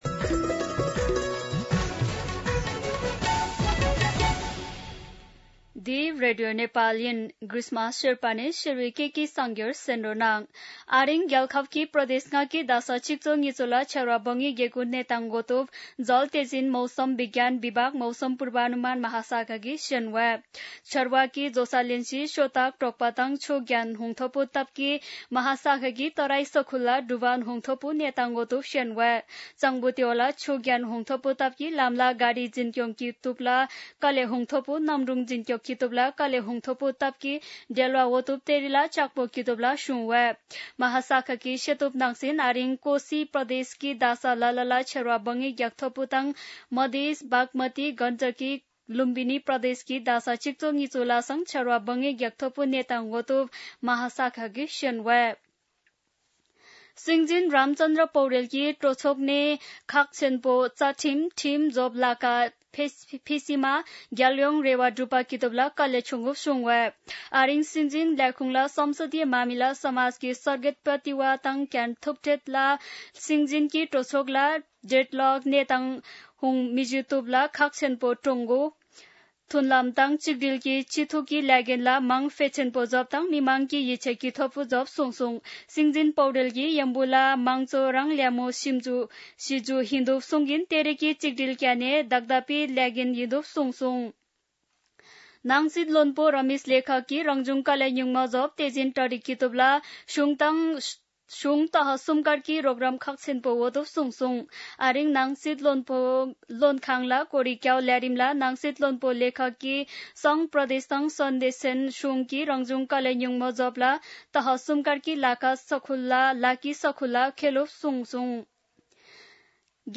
शेर्पा भाषाको समाचार : ३२ असार , २०८२